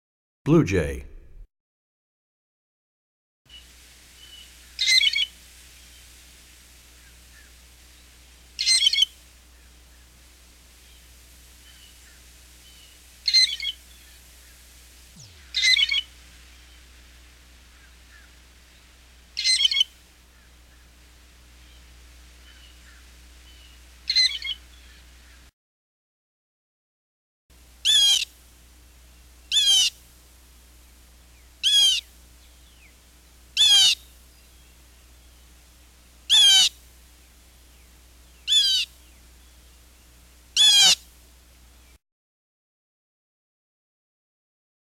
15 Blue Jay.mp3